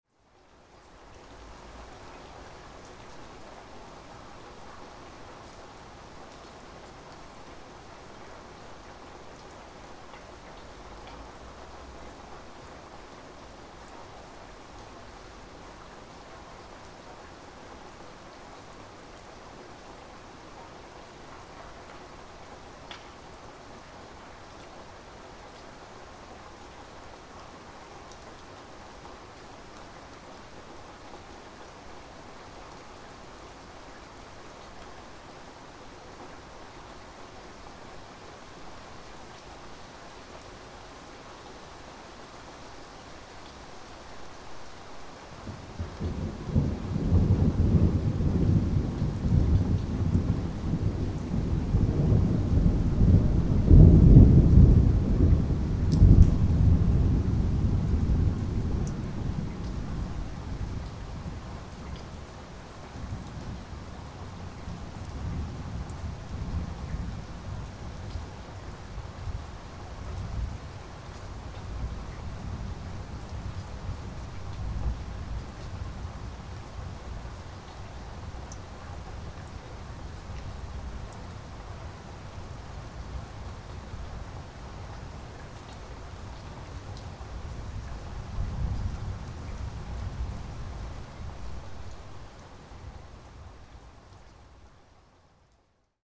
Rain Falling With Thunder Relaxing Storm Sound Effect
Description: Rain falling with thunder relaxing storm sound effect. Spring or summer storm with rain and thunder.
Genres: Sound Effects
Rain-falling-with-thunder-relaxing-storm-sound-effect.mp3